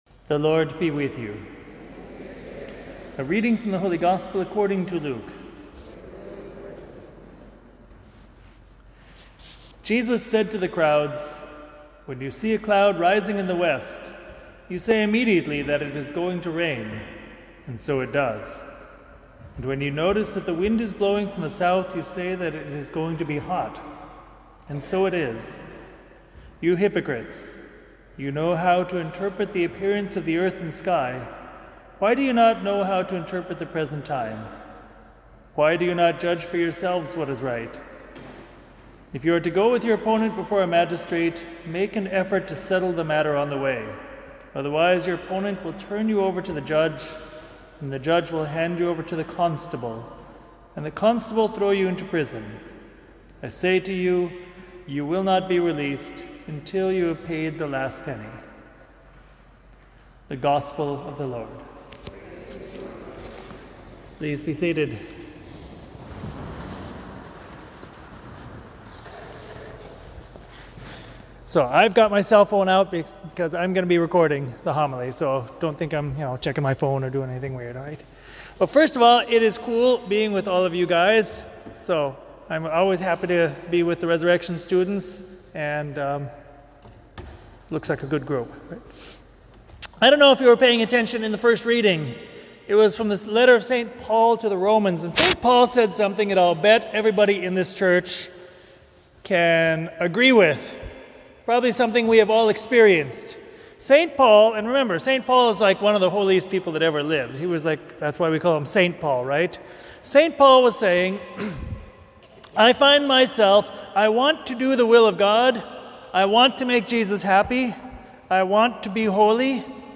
Homily
for the 11th Sunday in Ordinary Time at St. Patrick Church in Armonk, NY.